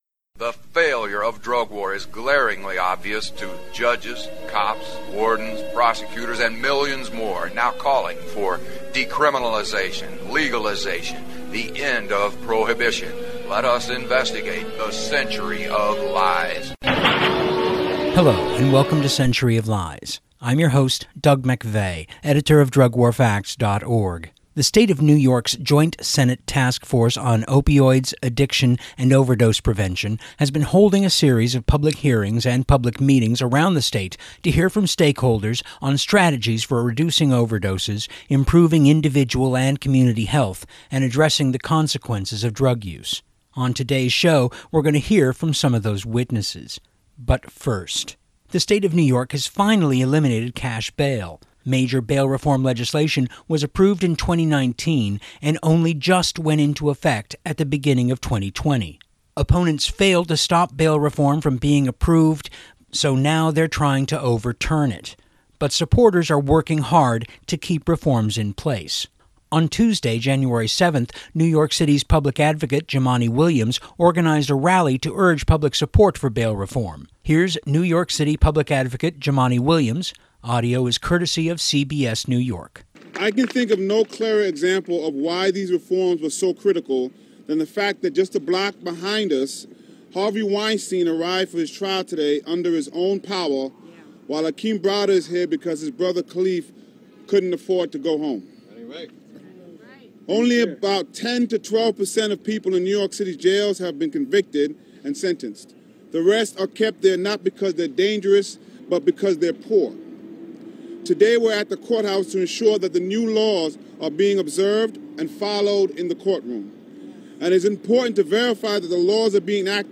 Plus, New York City's Public Advocate Jumaane Williams spoke at a rally on Tuesday Jan 7th to show support for bail reform. play pause mute unmute KBOO